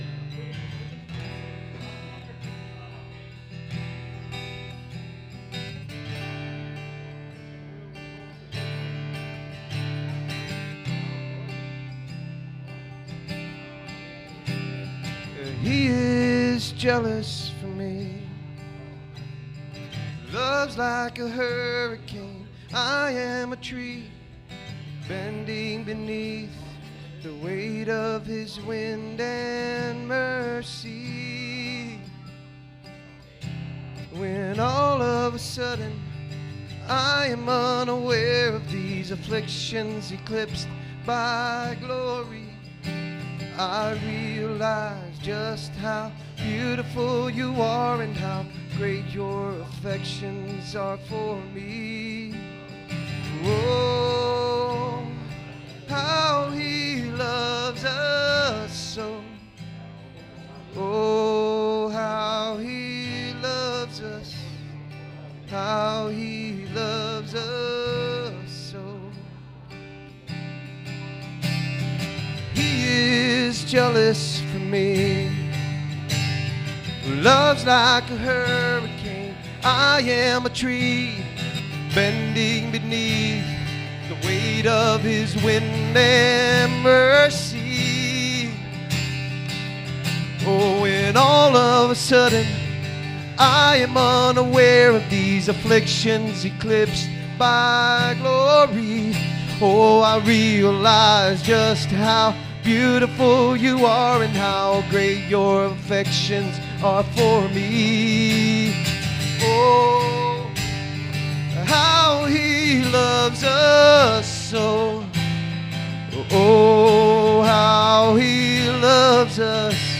SERMON DESCRIPTION God reveals a love that refuses to quit—one rooted not in human worthiness but in His covenant promise, even when patience is mistaken for approval.